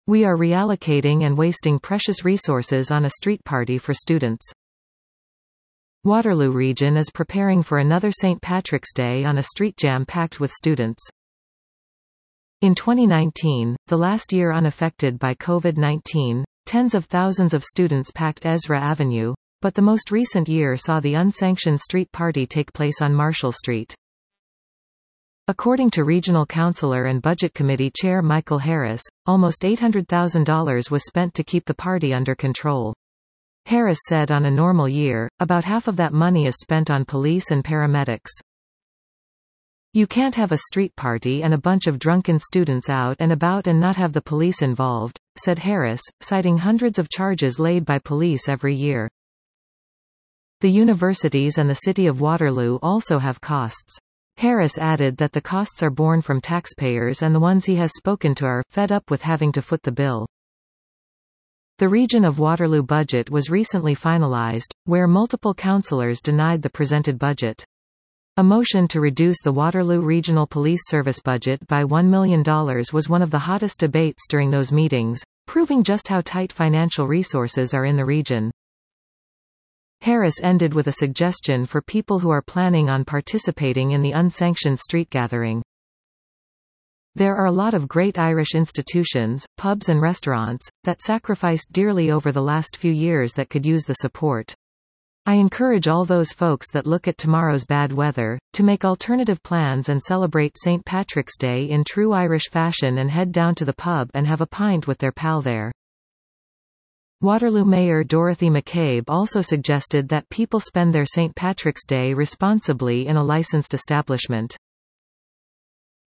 Budget Committee Chair Michael Harris spoke with CityNews 570 on Thursday